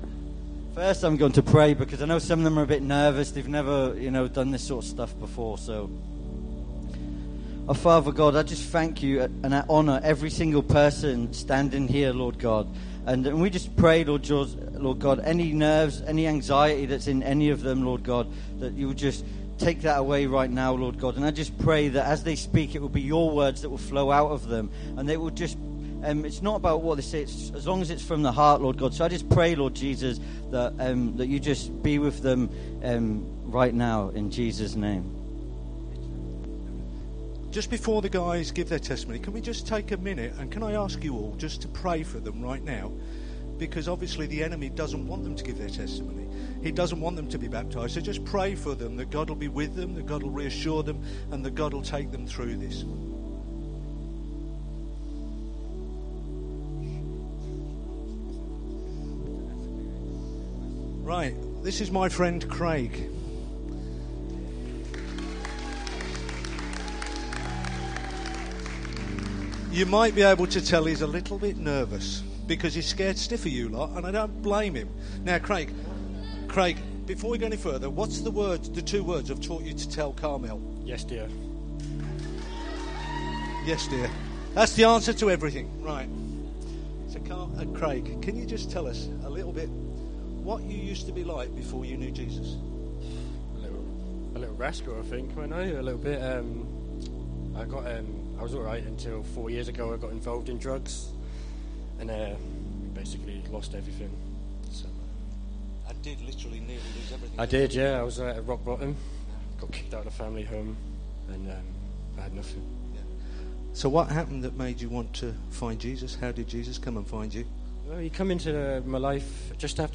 Baptism Testimonies
Today our sermon time is given over to seven new christians giving their testimonies about the difference knowing Jesus means to them and how their lives have been transformed.